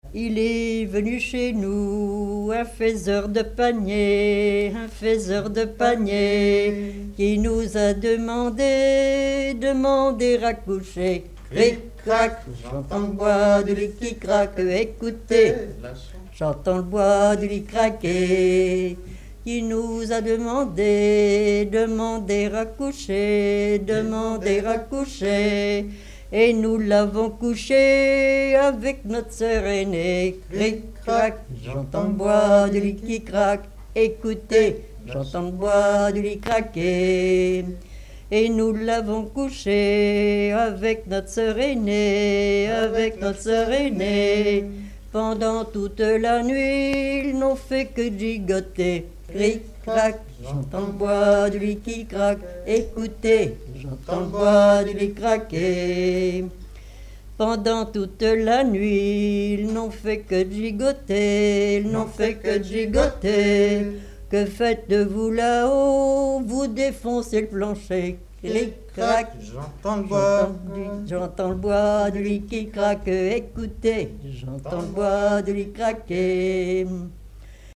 Enquête dans les Résidences de personnes âgées du Havre
Témoignages et chansons populaires
Pièce musicale inédite